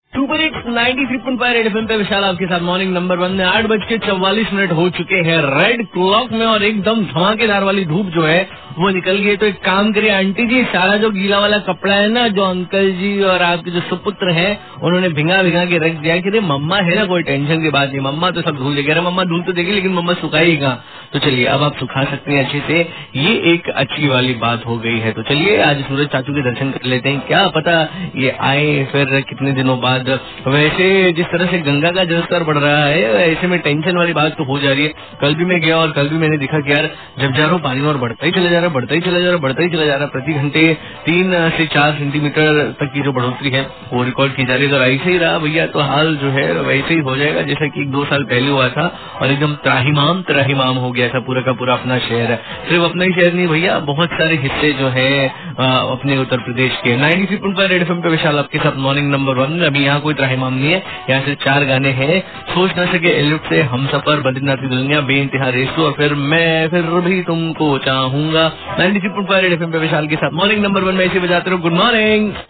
RJ TALKING ABOUT WEATHER